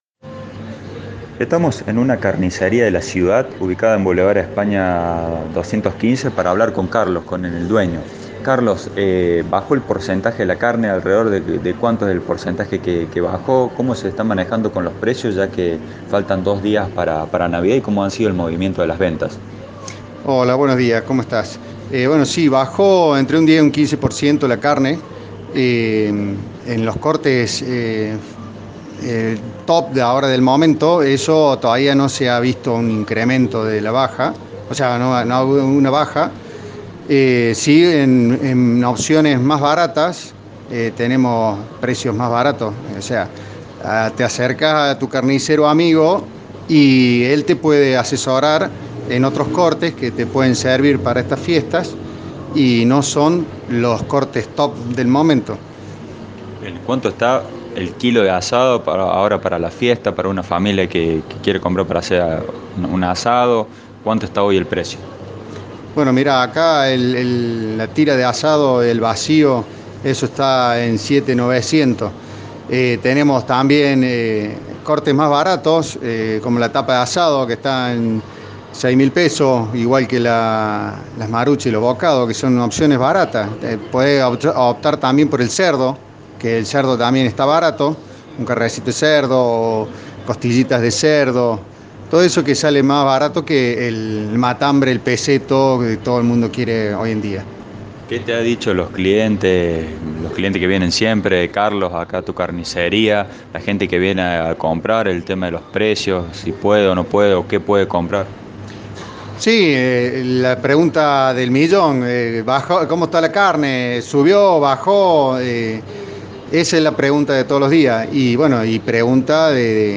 un carnicero local